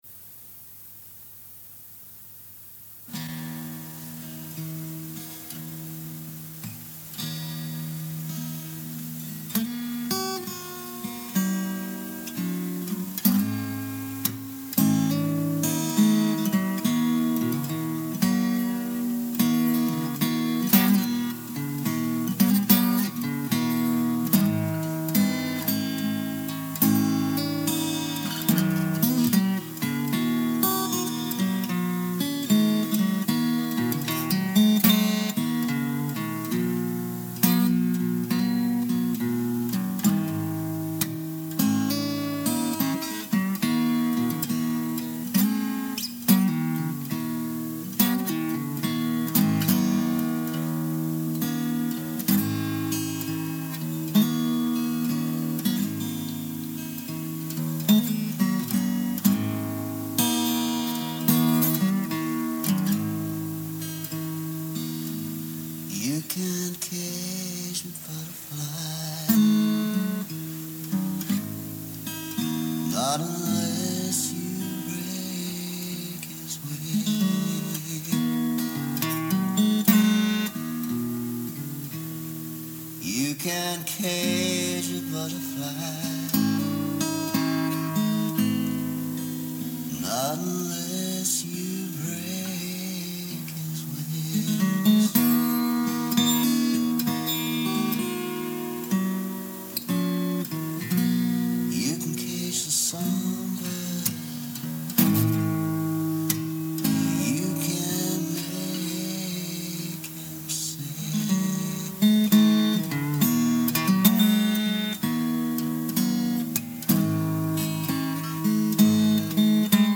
Recorded as a live video some time ago:
Here’s an audio version captured from the video and mastered to raise the levels slightly: